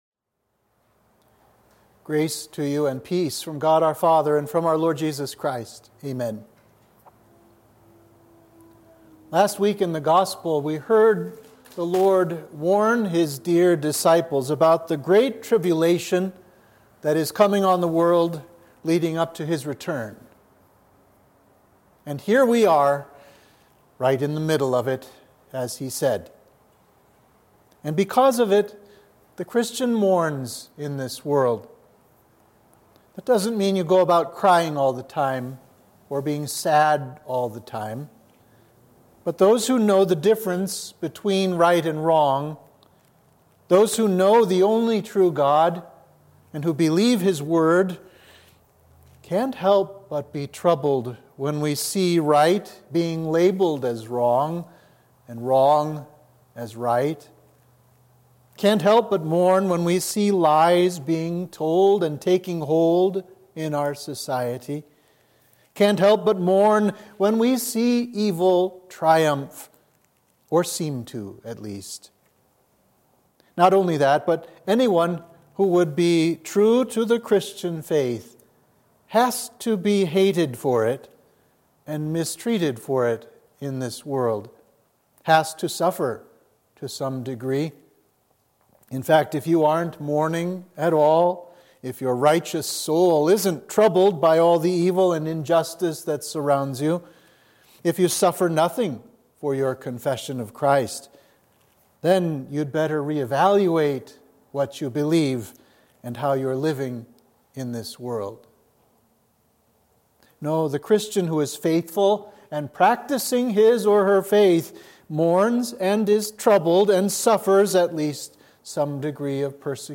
Sermon for Trinity 26